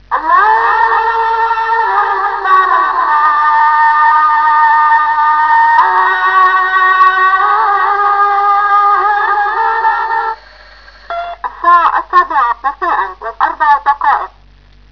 • vijf instelbare hazzan tijden waarop de moskee tot gebed oproept
• zegt de  tijd in het Arabisch
moskee.wav